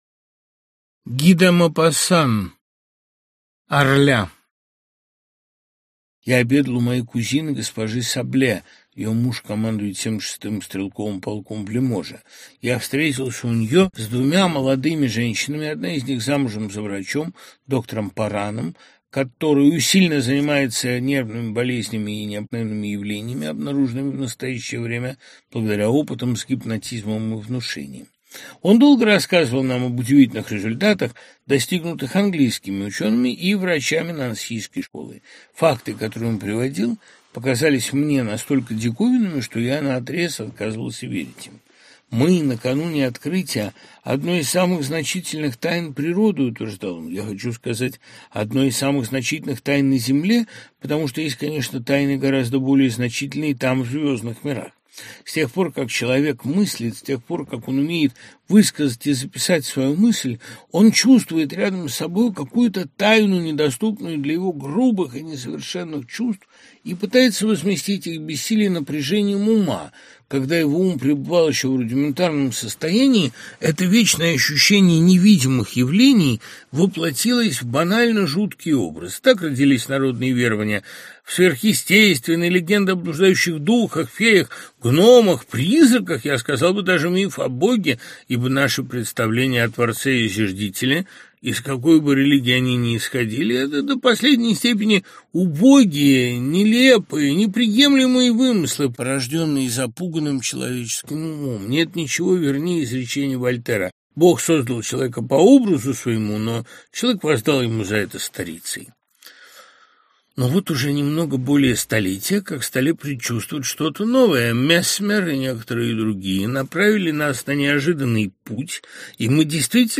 Аудиокнига Ги де Мопассан. Орля в исполнении Дмитрия Быкова + Лекция Быкова | Библиотека аудиокниг
Орля в исполнении Дмитрия Быкова + Лекция Быкова Автор Дмитрий Быков Читает аудиокнигу Дмитрий Быков.